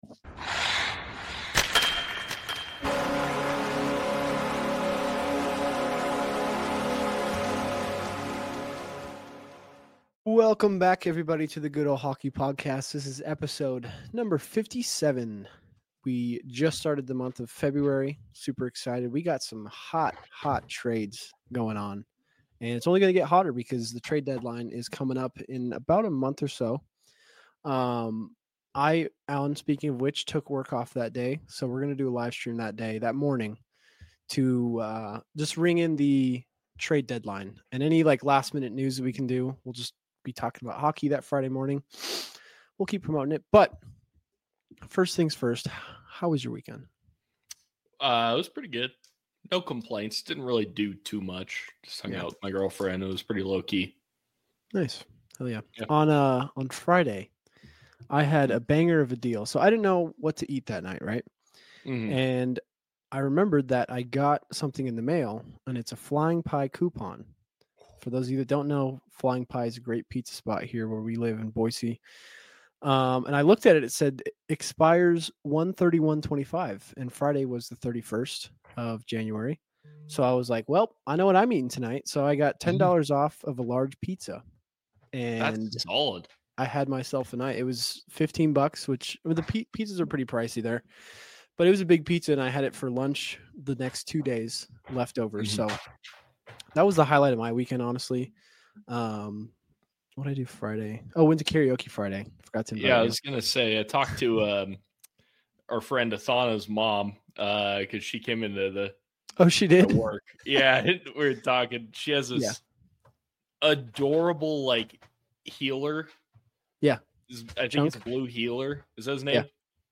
They discuss the big trades including those between the Sharks and Dallas, New York Rangers and Vancouver Canucks, and Calgary with Philadelphia. The episode also features reactions to fan voicemails, thoughts on improving the NHL All-Star Game, and a discussion on the Utah Hockey Club's naming options.